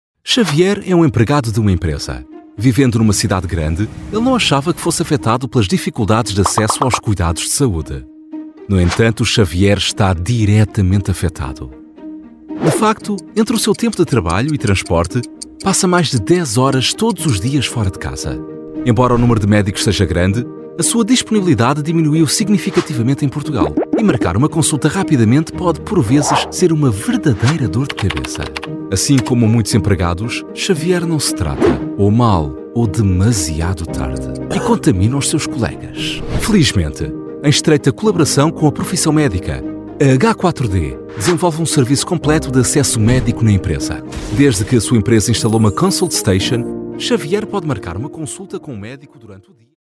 Voice Samples: Web Video
male